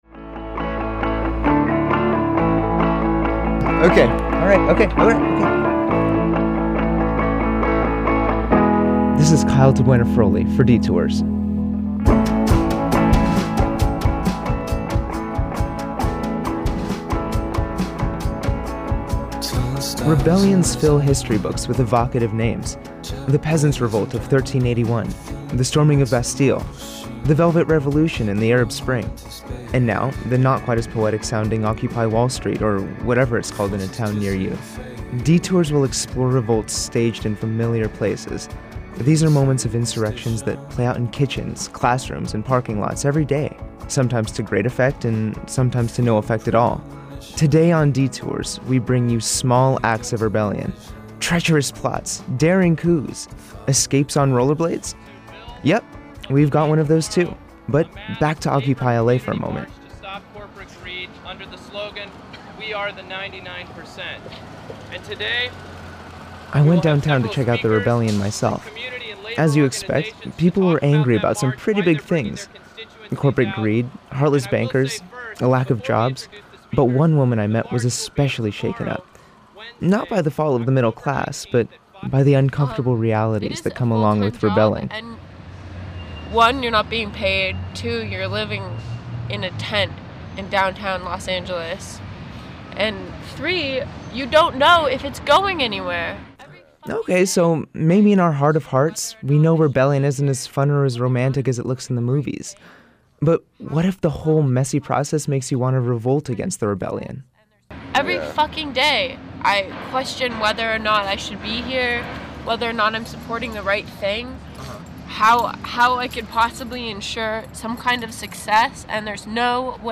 Detours is an offbeat podcast with creative, boundary-pushing audio.